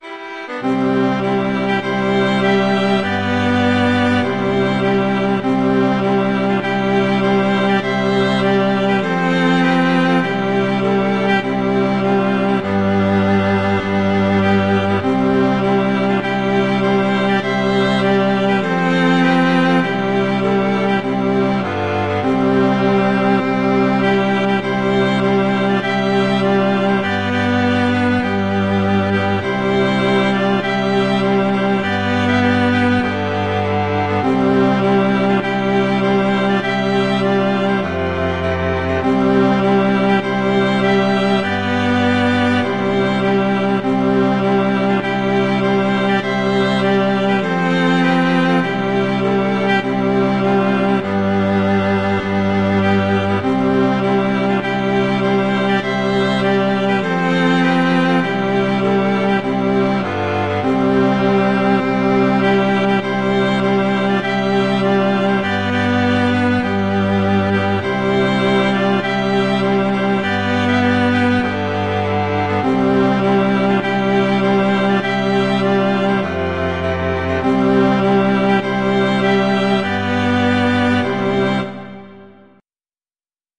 traditional, irish